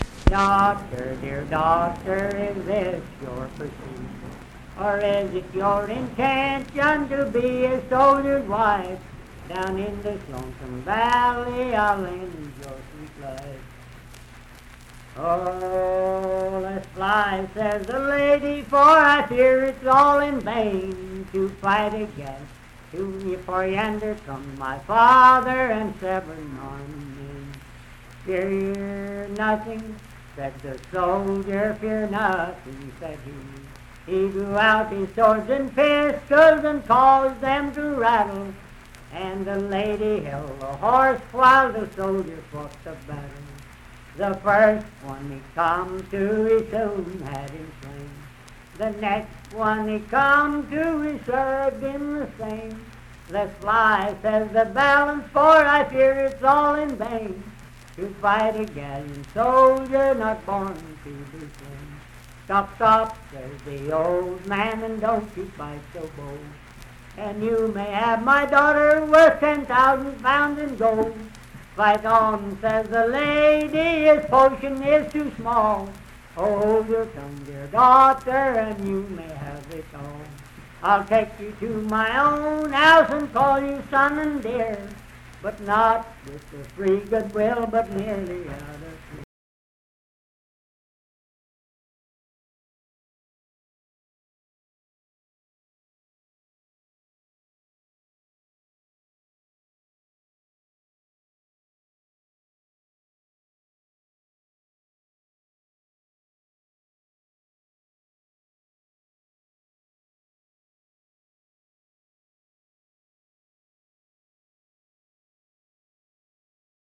Unaccompanied vocal music and folktales
Verse-refrain 7(2-4).
Voice (sung)
Wood County (W. Va.), Parkersburg (W. Va.)